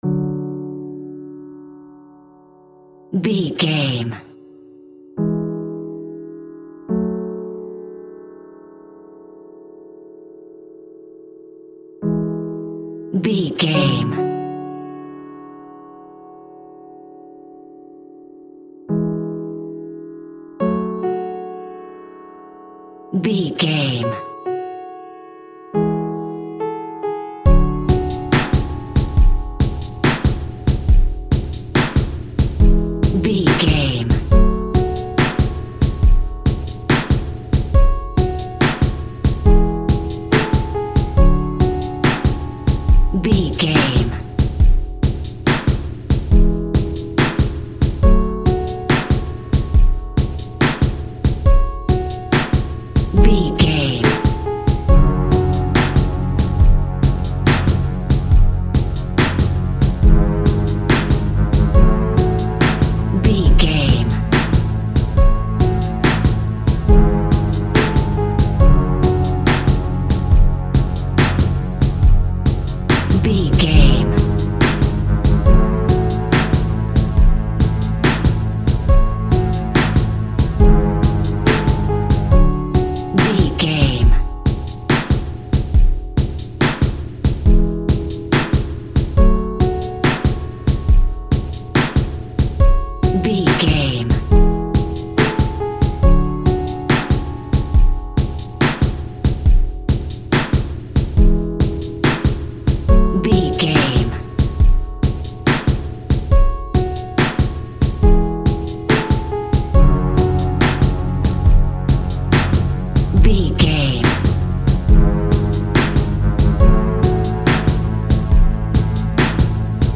Aeolian/Minor
F#
instrumentals
chilled
laid back
groove
hip hop drums
hip hop synths
hip hop pads